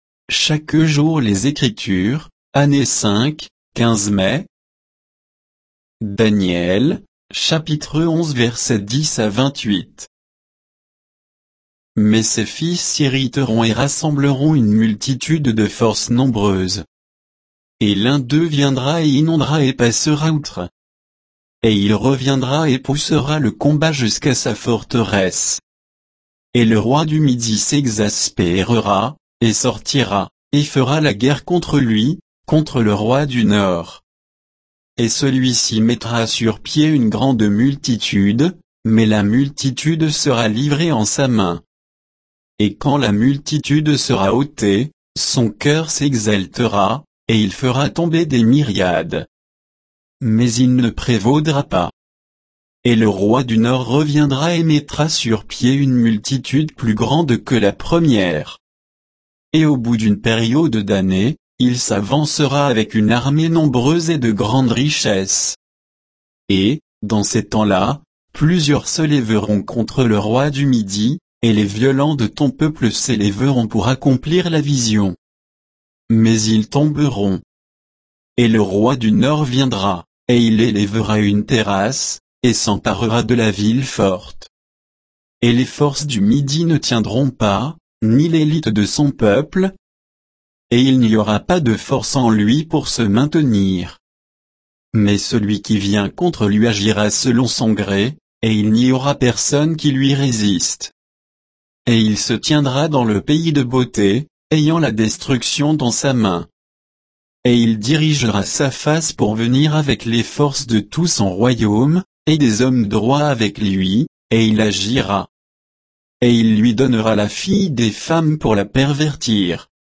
Méditation quoditienne de Chaque jour les Écritures sur Daniel 11, 10 à 28